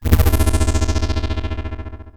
Hum35.wav